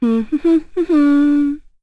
Epis-Vox_Hum1.wav